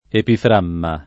vai all'elenco alfabetico delle voci ingrandisci il carattere 100% rimpicciolisci il carattere stampa invia tramite posta elettronica codividi su Facebook epifragma [ epifr #g ma ] o epiframma [ epifr # mma ] s. m.; pl.